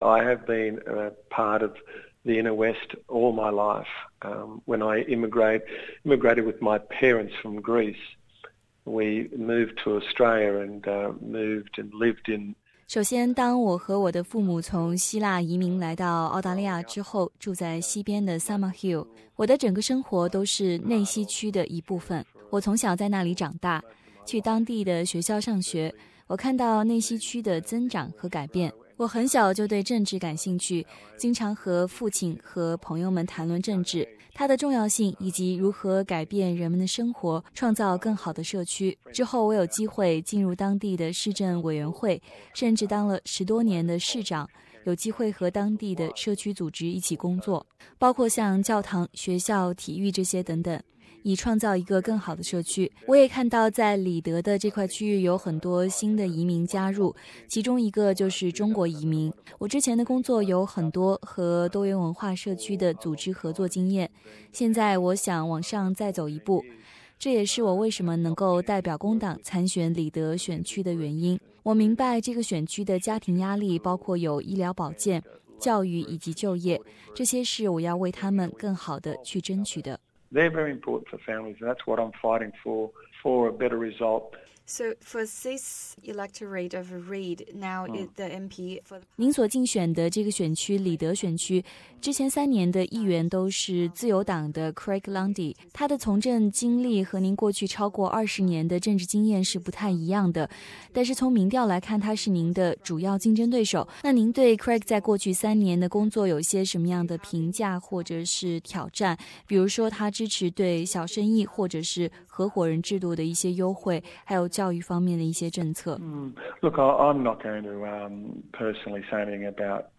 里德选区工党候选人安吉罗.泰域卡斯采访